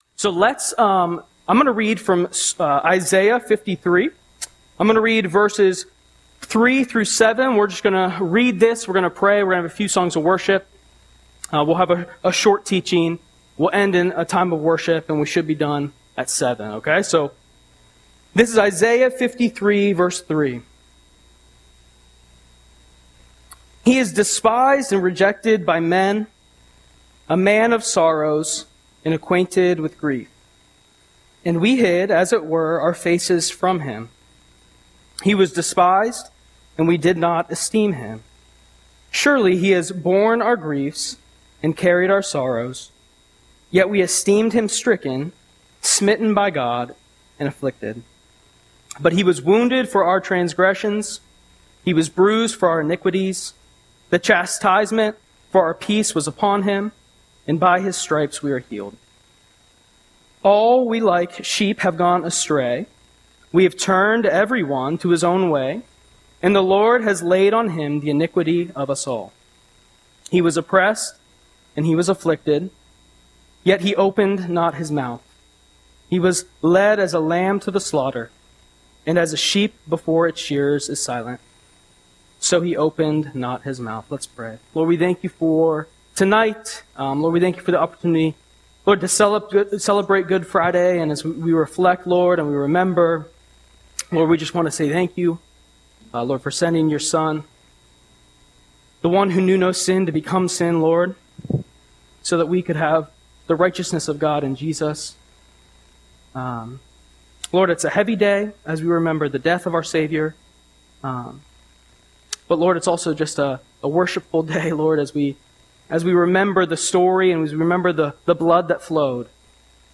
Audio Sermon - April 18, 2025